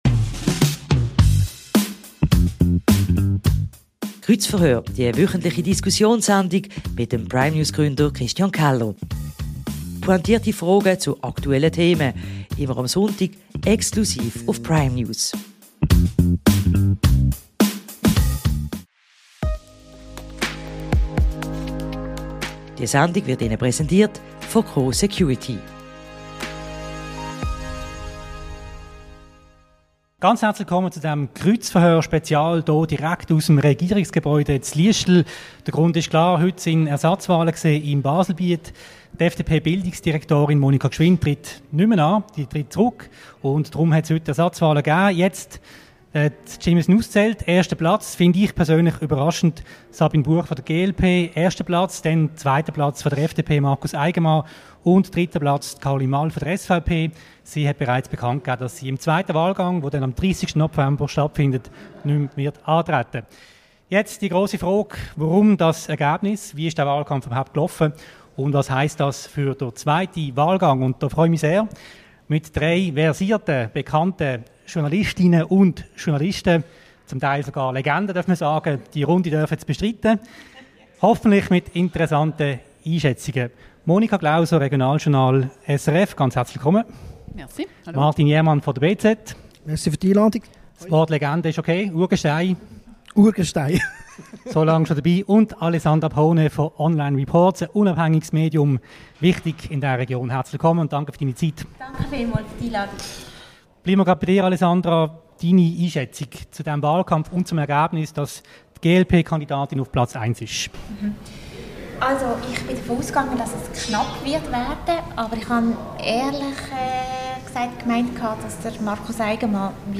Journalistenrunde zu den Baselbieter Wahlen ~ Kreuzverhör Podcast